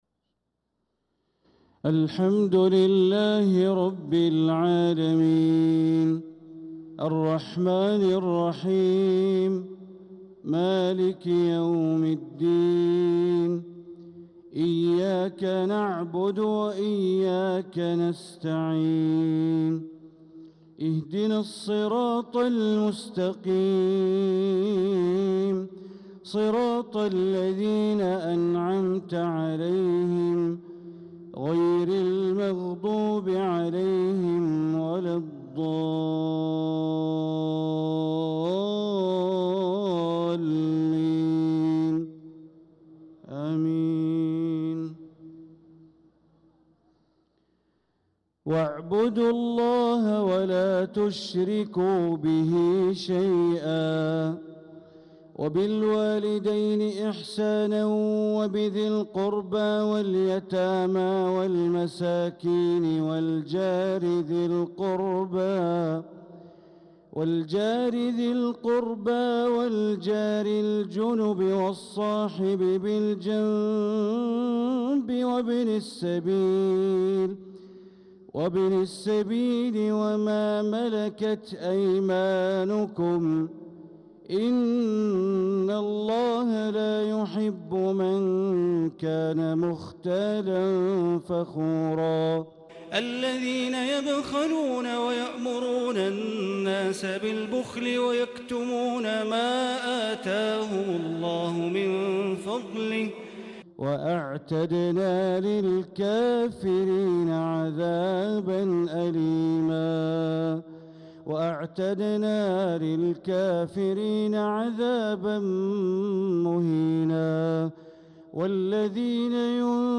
صلاة الفجر للقارئ بندر بليلة 11 ذو الحجة 1445 هـ
تِلَاوَات الْحَرَمَيْن .